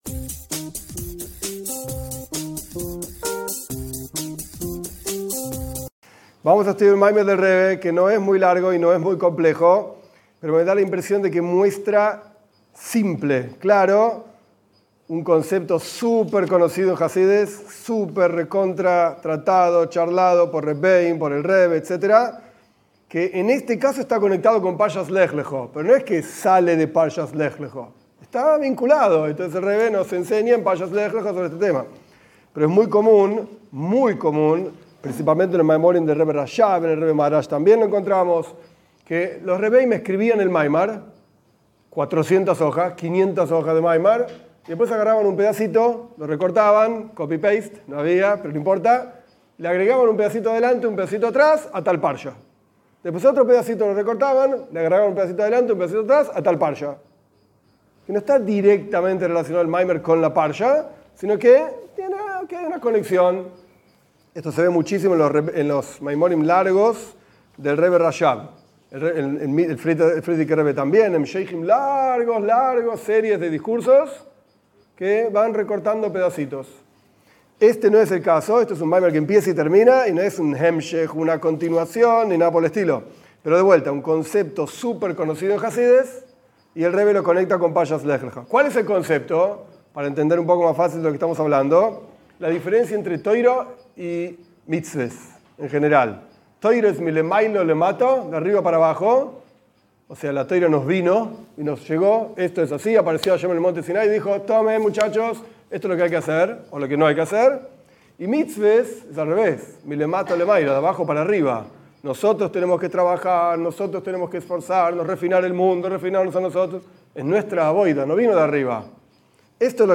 A la media hora terminó el tiempo de clase... terminé el discurso en mi oficina...